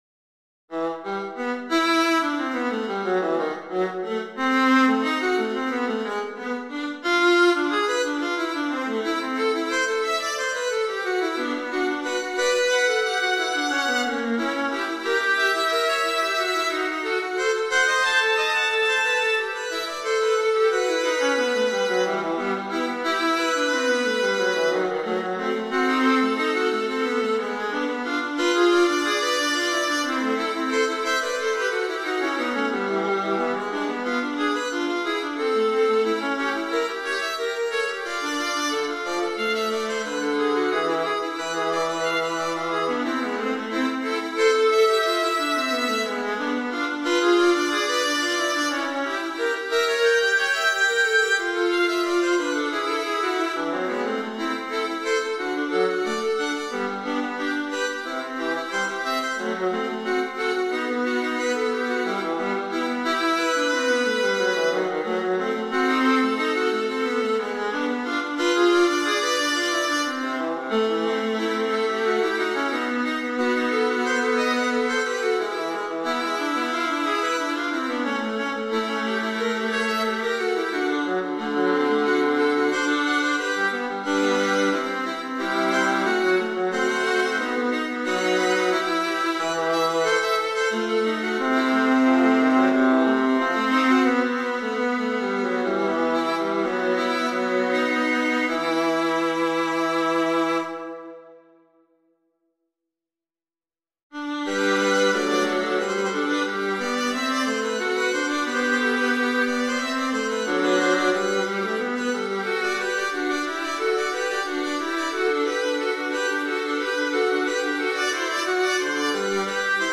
viola duos